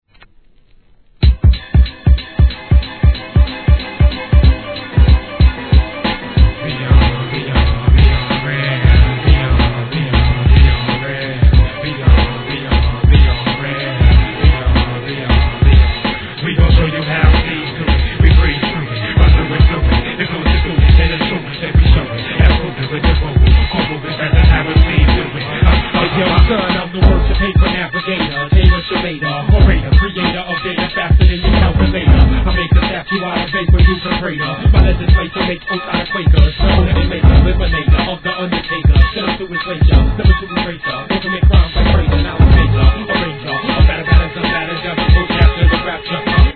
HIP HOP/R&B
このハイクオリティーなサウンド、さすがです。